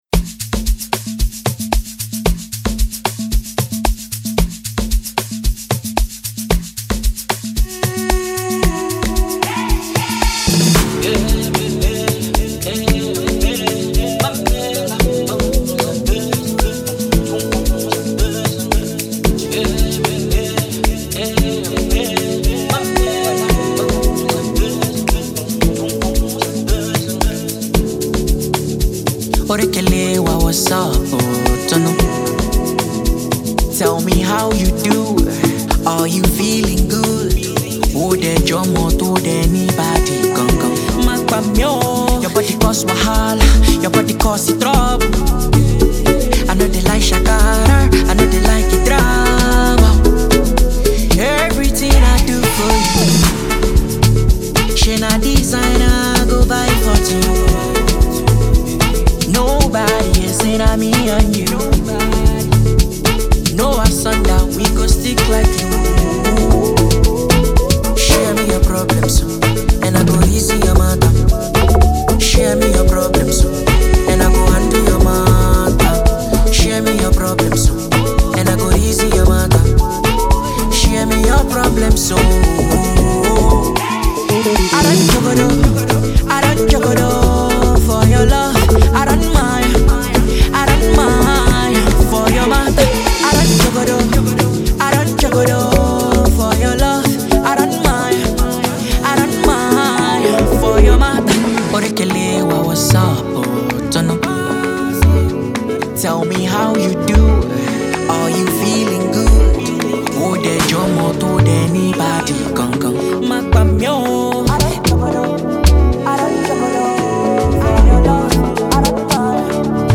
Amapiano record